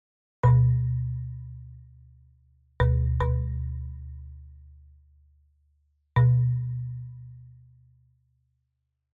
Finally, physical modeling synthesis is a relatively recent synthesis which imitates an instrument on the basis of its physical characteristics: its shape, its size its material, its density, etc. With physical modeling, we can replicate the sound of any instrument, even a wooden cymbal 4 meters in diameter!
Wood-cymbal.mp3